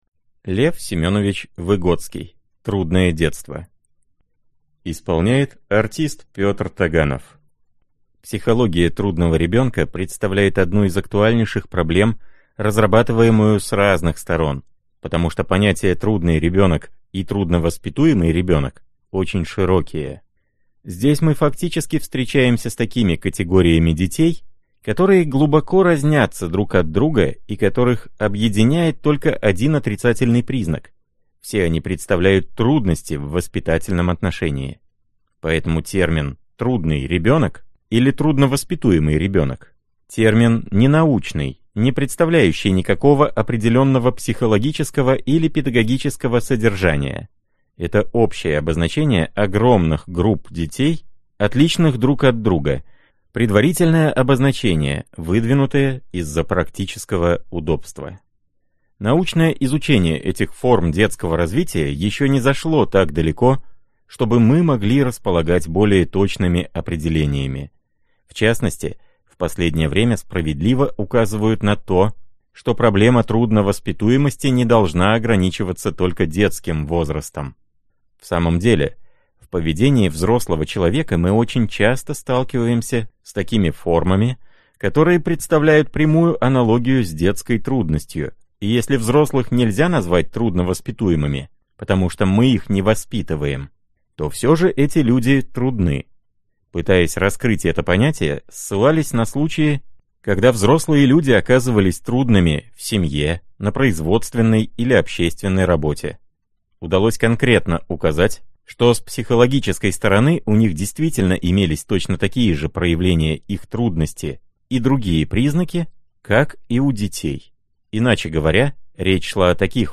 Аудиокнига Трудное детство. Диагностика и развитие трудного ребенка.
Прослушать и бесплатно скачать фрагмент аудиокниги